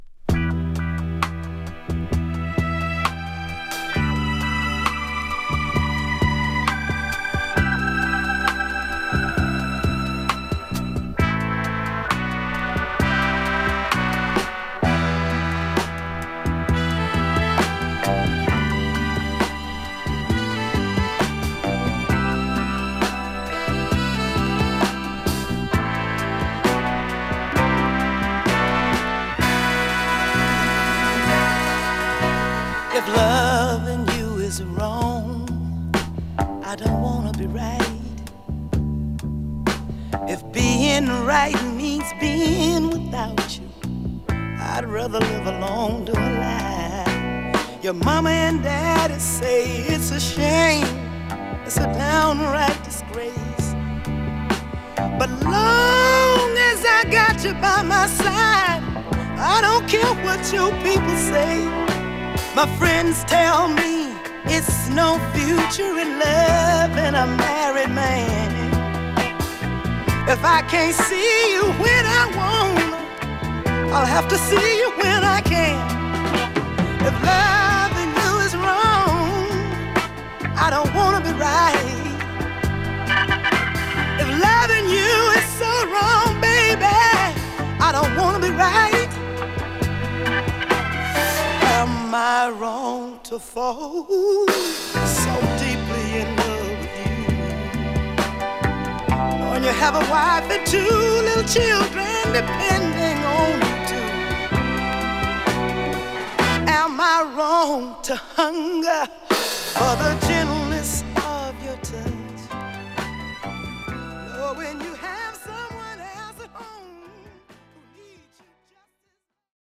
2. > SOUL/FUNK
女性ソウル〜R&Bシンガー